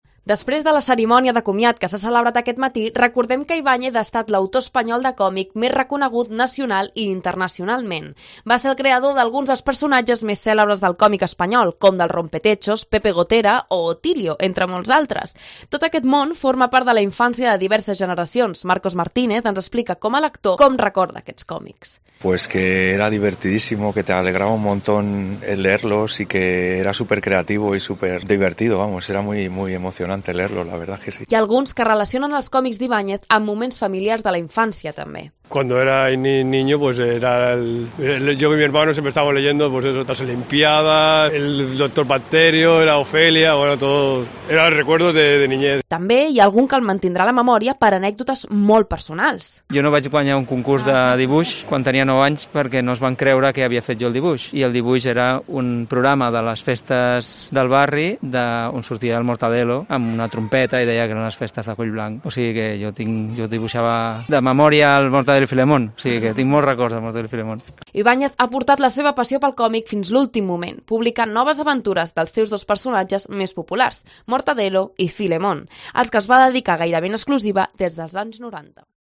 Crònica